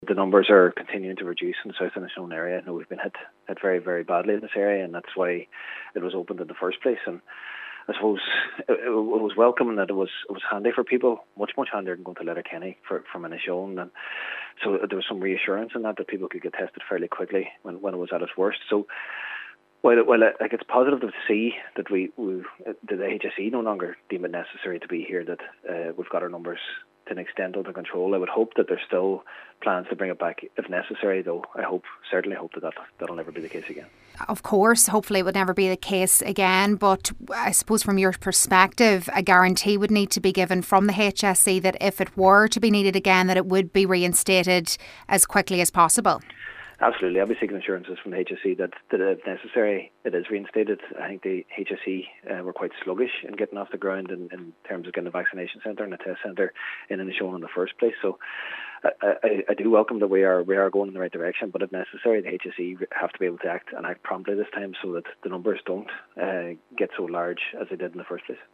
Cathaoirleach of Donegal County Council Cllr Jack Murray says it’s clear the HSE don’t deem it necessary for the facility to remain there which is positive.